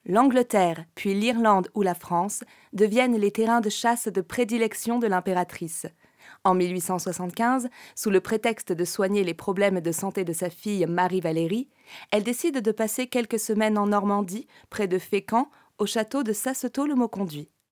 Voix off
20 - 45 ans - Mezzo-soprano